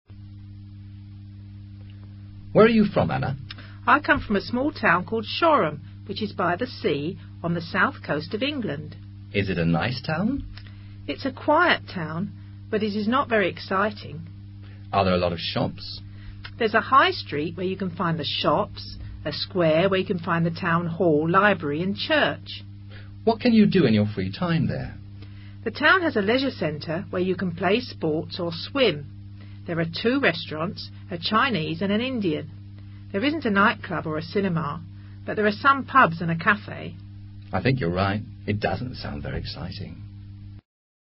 Una muchacha le describe a una amiga su ciudad.
Este diálogo es útil para reforzar, además de la comprensión auditiva, la utilización de las siguientes estructuras gramaticales de la lengua inglesa: there is-are, and-or, relative clauses using where + subject, además de vocabulario para pequeñas ciudades.